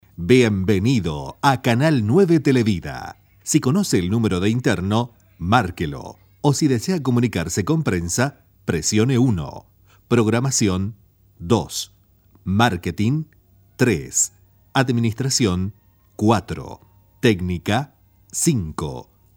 spanisch SĂŒdamerika
Sprechprobe: Industrie (Muttersprache):